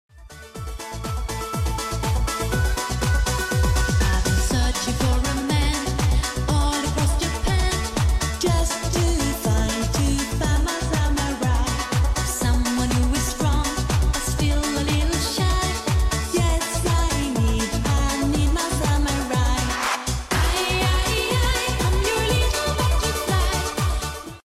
i like thunderbolt siren sound effects free download